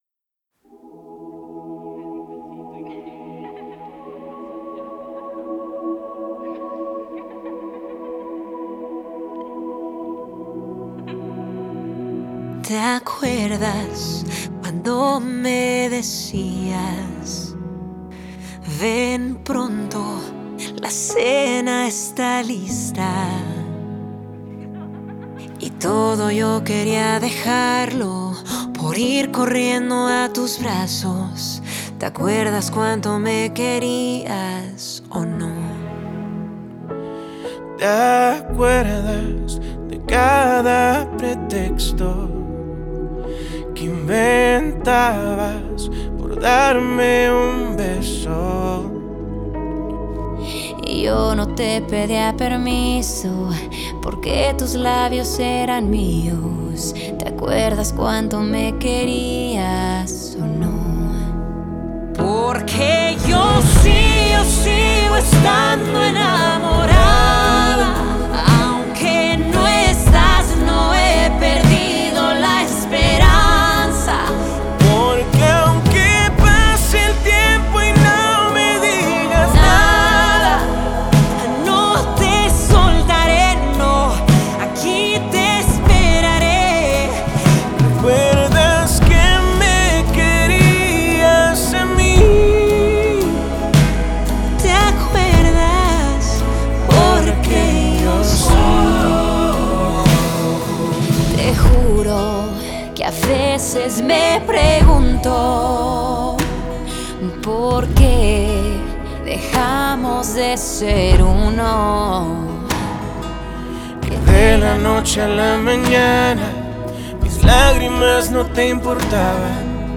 dentro del pop en México